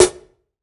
SNARE 059.wav